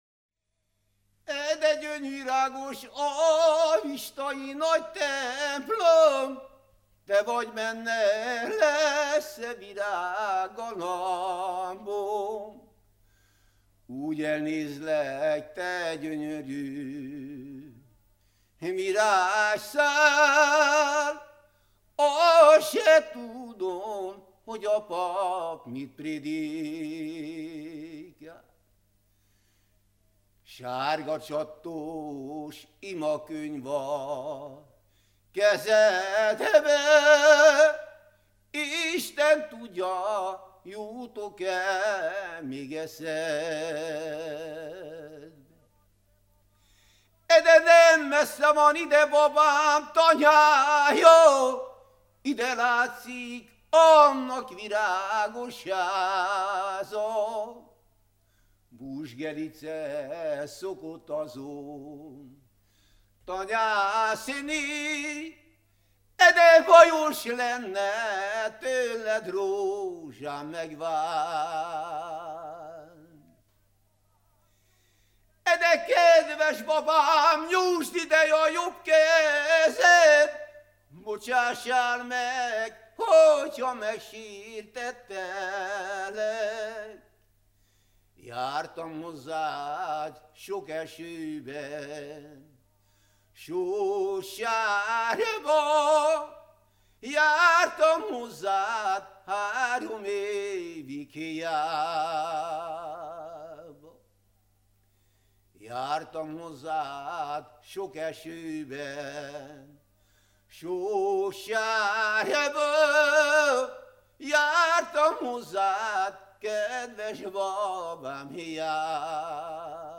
ének
Türe
Nádas mente (Kalotaszeg, Erdély)